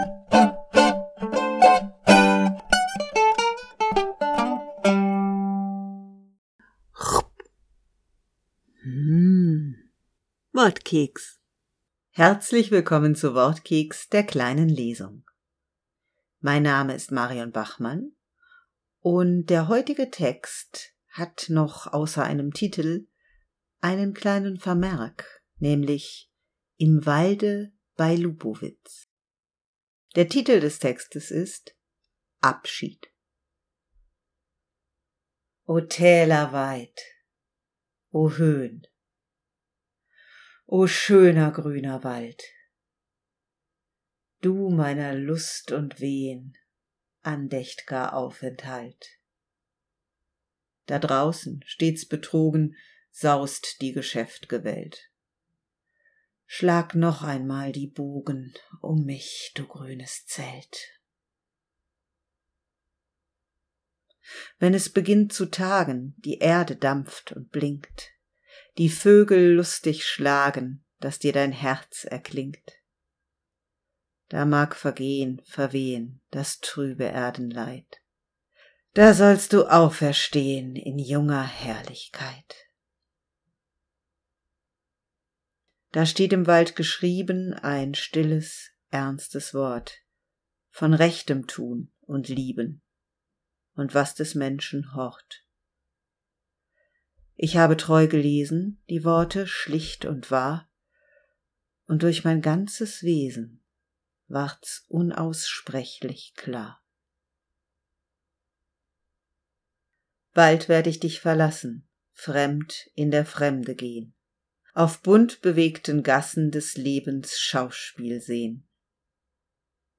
Kleine Lesung, die man als Quiz nutzen kann, heute aus dem Wald.